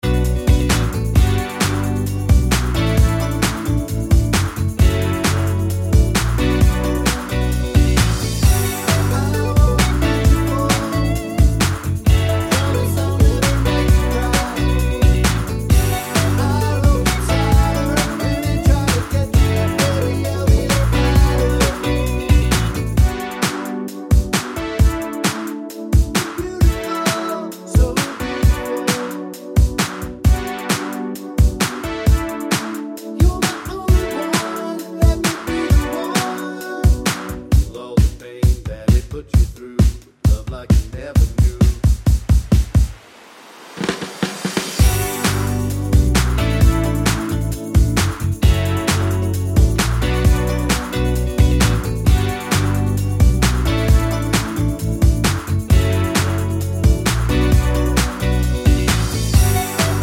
no Backing Vocals Duets 3:06 Buy £1.50